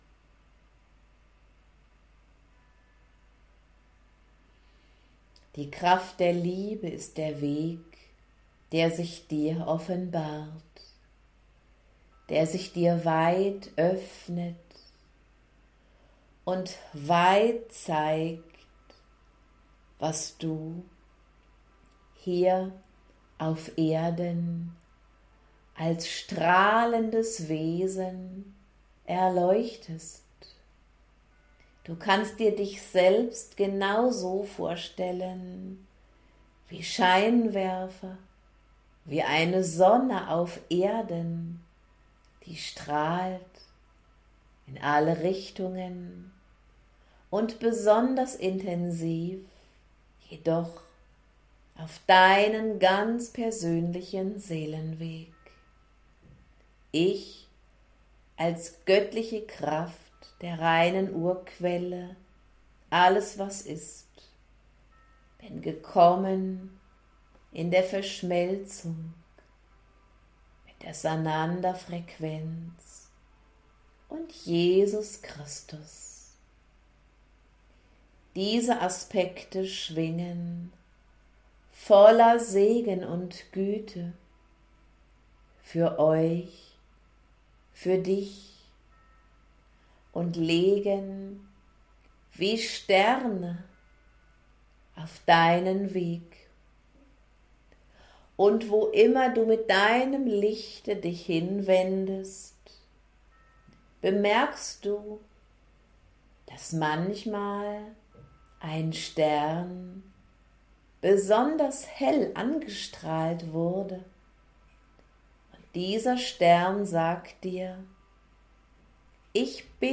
Jesus Christus Sananda mit der Göttlichen Quelle Alles was ist reine Liebe, tolle stärkende Energie im Monatschanneling - Genieße einen Auszug GRATIS für dich!